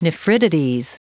Prononciation du mot nephritides en anglais (fichier audio)
Prononciation du mot : nephritides